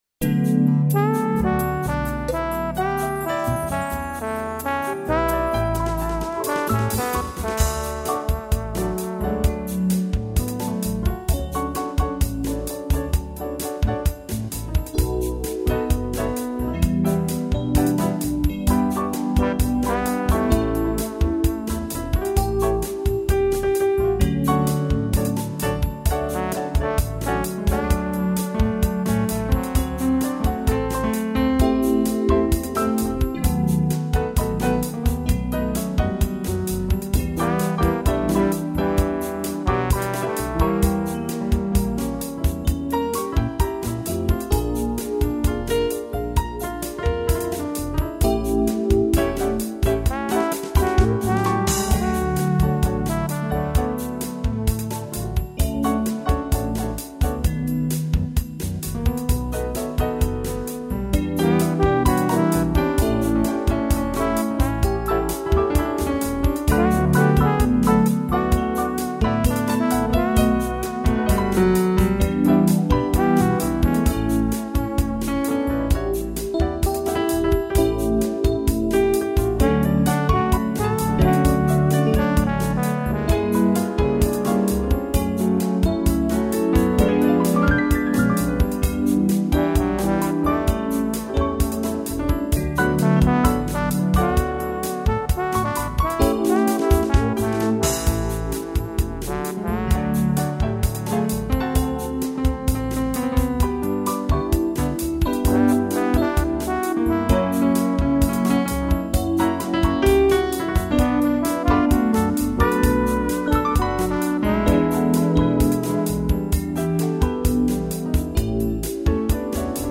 piano e trombone
instrumental